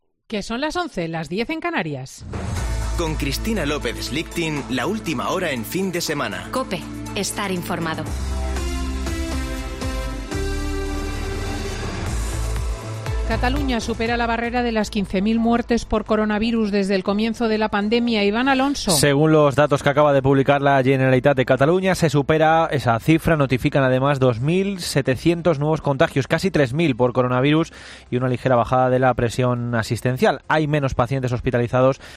Boletín de noticias de COPE del 14 de noviembre de 2020 a las 11.00 horas